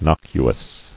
[noc·u·ous]